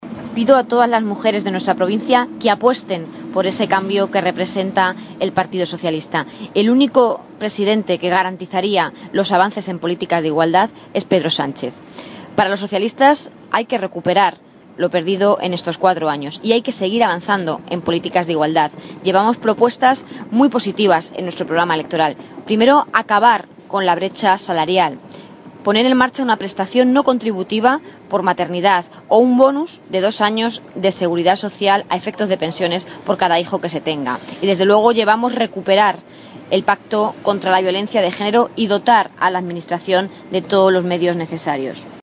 Isabel Rodríguez ha realizado estas declaraciones en Zaragoza en el marco de un acto del candidato del PSOE a la Presidencia del Gobierno con todas las mujeres que encabezan las distintas candidaturas al Congreso de los Diputados.
Cortes de audio de la rueda de prensa